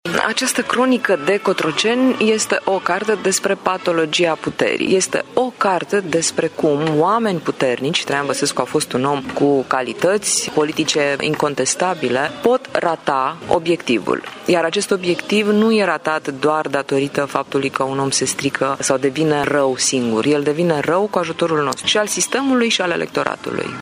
Într-un interviu acordat în exclusivitate pentru Radio Tîrgu-Mureș, Adriana Săftoiu consideră că ”un om nu devine rău sau bun singur”: